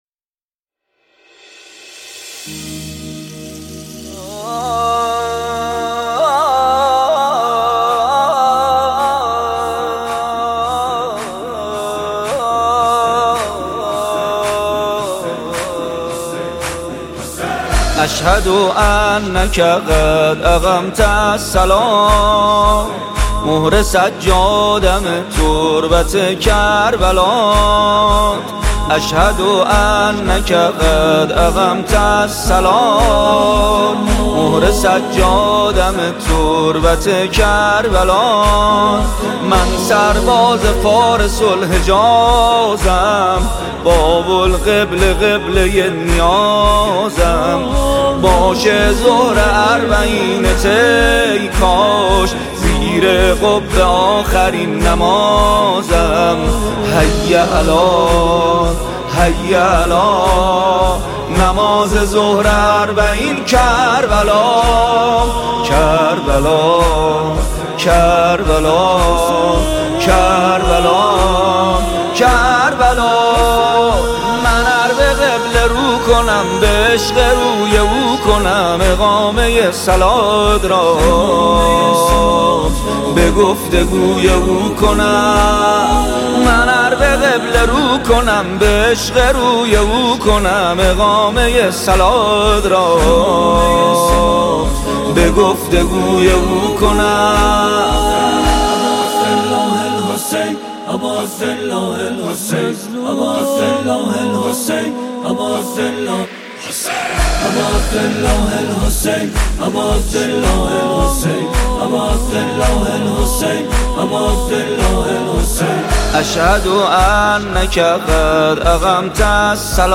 نواهنگ زیبا و دلنشین